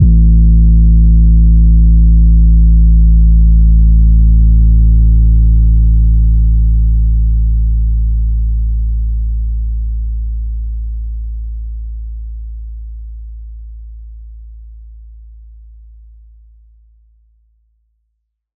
• phonk kicks oneshot 31 - 808 A.wav
Specially designed for phonk type beats, these nasty, layered 808 one shots are just what you need, can also help designing Hip Hop, Trap, Pop, Future Bass or EDM.
phonk_kicks_oneshot_31_-_808_A_eEF.wav